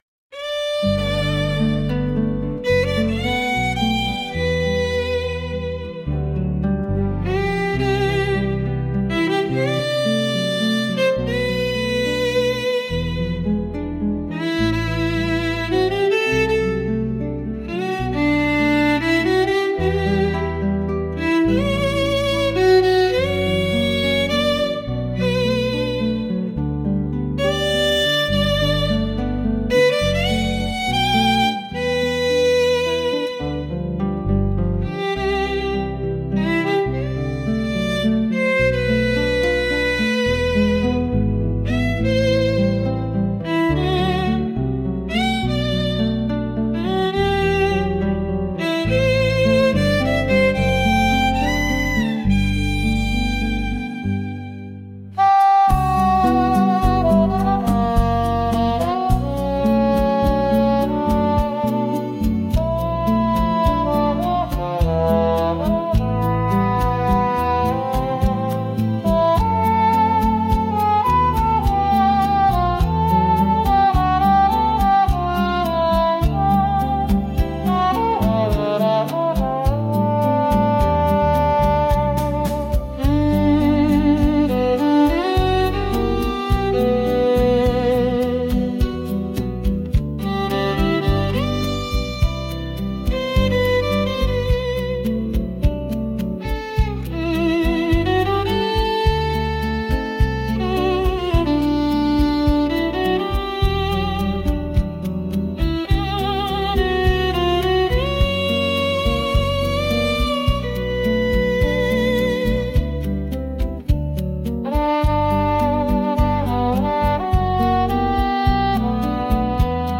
música, arranjo e voz: IA) instrumental